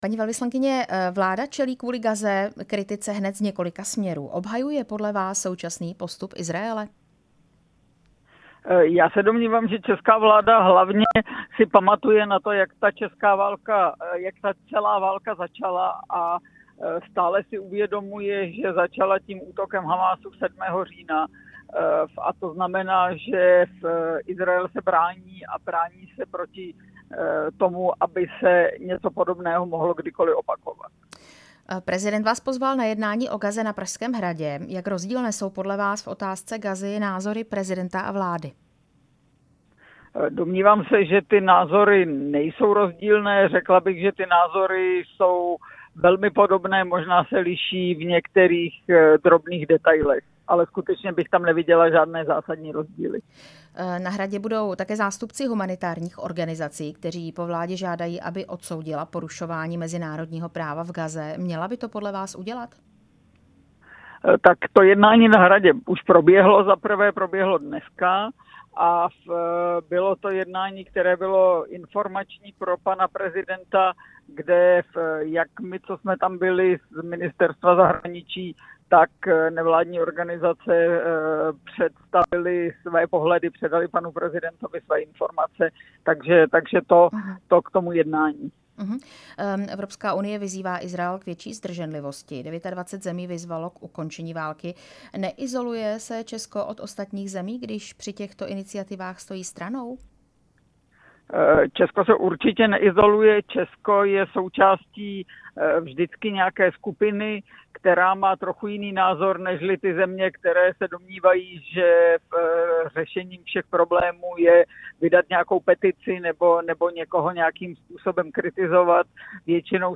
Rozhovor s velvyslankyní v Izraeli Veronikou Kuchyňovou Šmigolovou